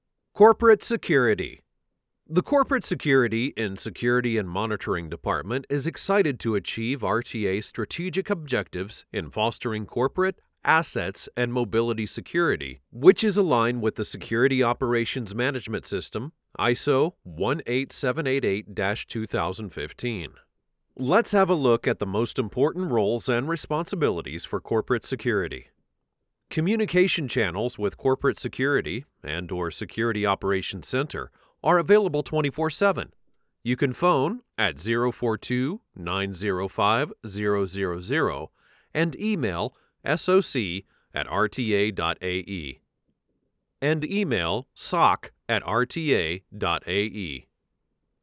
English (American)
Deep, Natural, Friendly, Warm, Corporate
E-learning